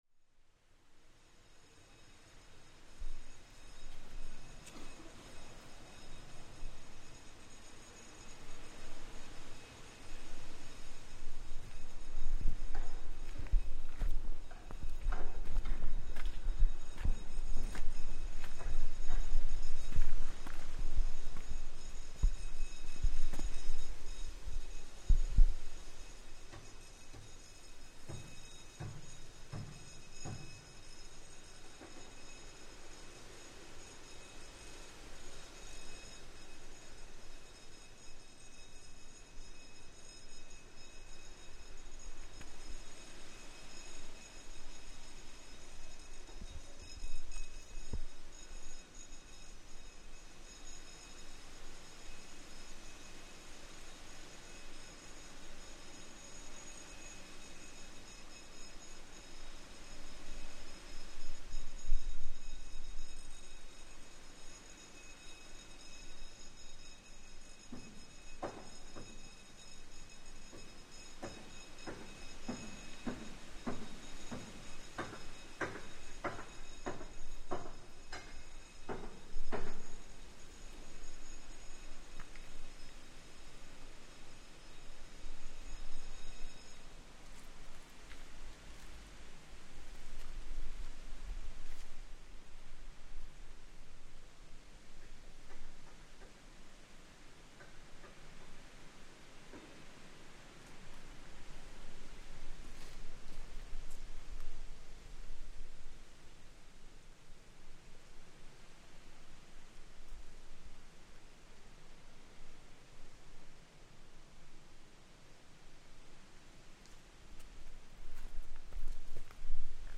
This recording captures the working life of the population living on the islands, as work takes place on a boat in the dry dock, with a large crane moving back and forth.